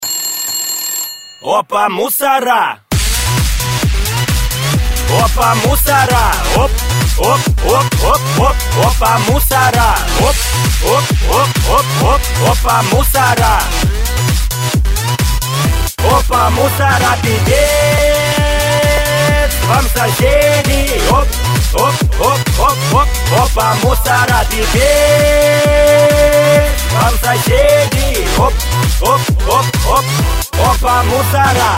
Категория: Рингтоны приколы